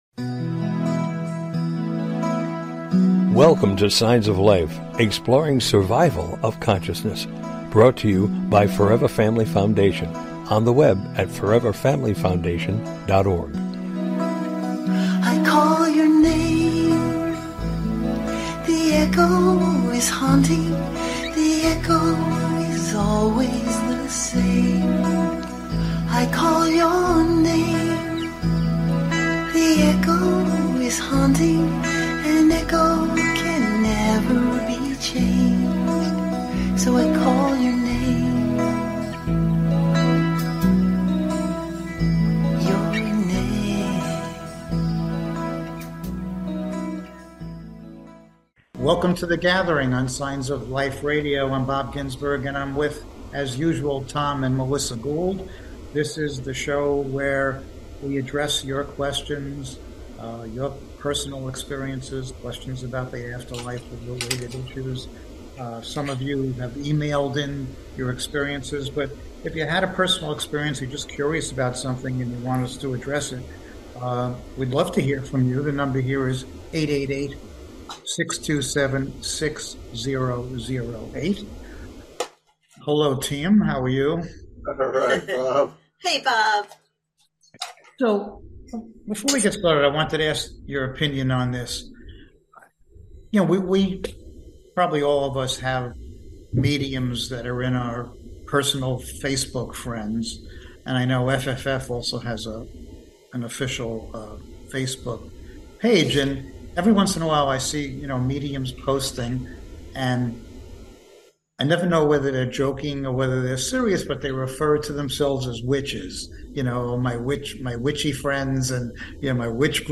Signs of Life Radio is tonight!
Topic: The Gathering: Listener Questions - CALL US LIVE!